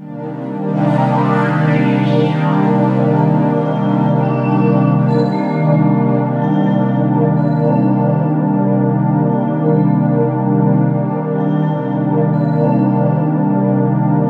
Index of /90_sSampleCDs/Soundscan - Ethereal Atmospheres/Partition B/04-SWEEP A
SWEEP04   -R.wav